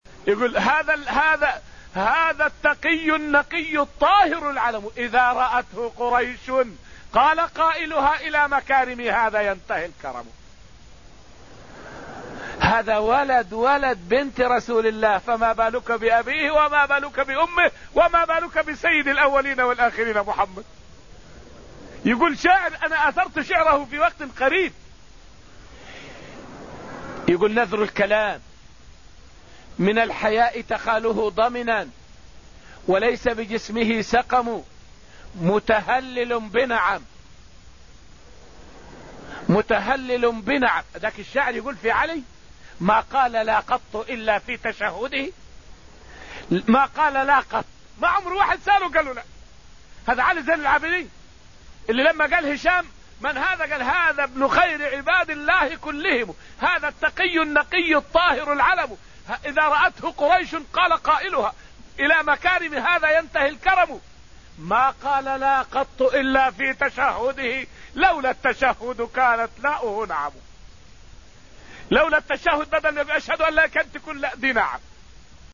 فائدة من الدرس الخامس من دروس تفسير سورة الحديد والتي ألقيت في المسجد النبوي الشريف حول فضل زين العابدين بن علي رضي الله عنه.